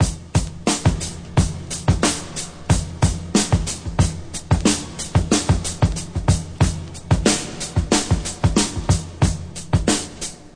• 91 Bpm Breakbeat Sample F Key.wav
Free breakbeat sample - kick tuned to the F note.
91-bpm-breakbeat-sample-f-key-ye0.wav